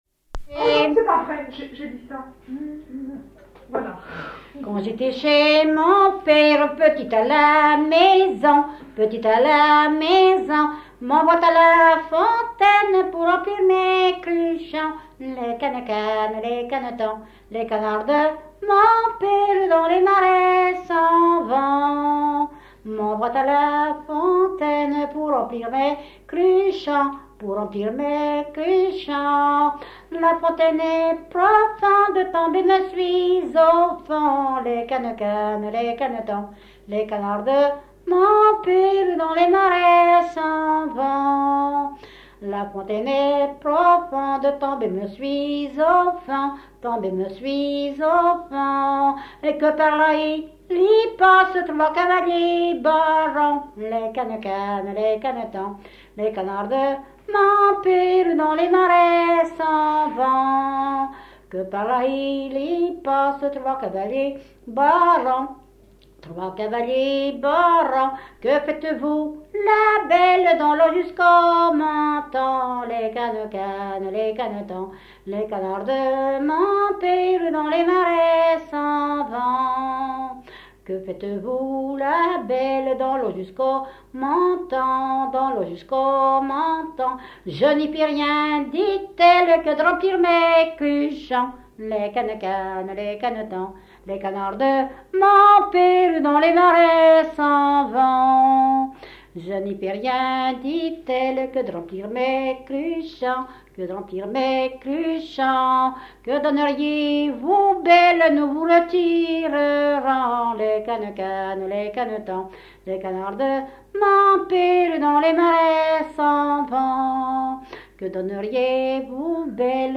Ile de Noirmoutier
Genre laisse
Catégorie Pièce musicale inédite